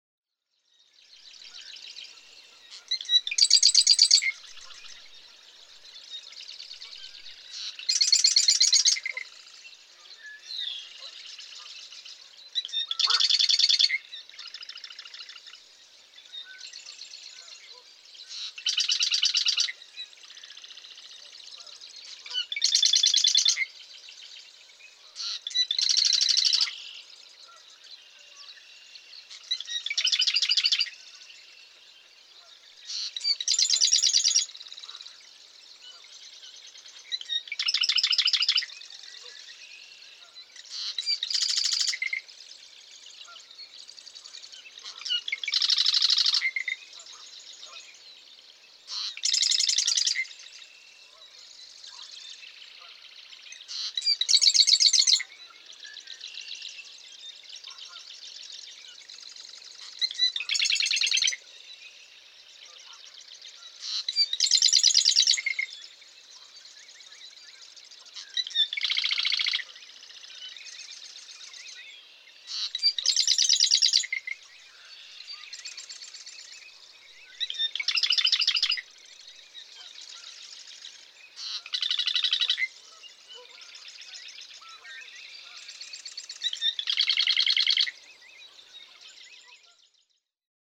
Marsh wren
Songs of the eastern marsh wren, sounding more liquid, more homogeneous, with less variety.
Parker River National Wildlife Refuge, Newburyport, Massachusetts.
108_Marsh_Wren.mp3